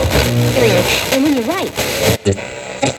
80BPM RAD2-R.wav